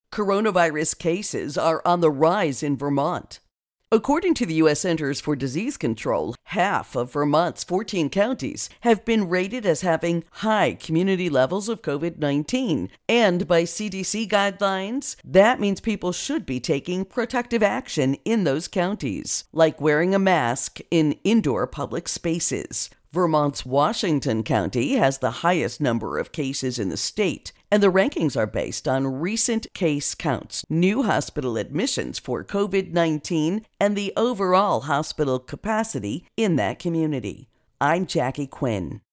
Virus Outbreak Vermont Intro and Voicer